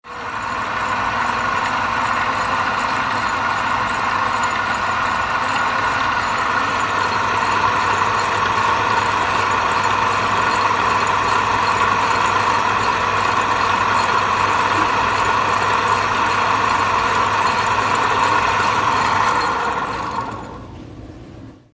Звуки мясорубки
Промышленная мясорубка производит фарш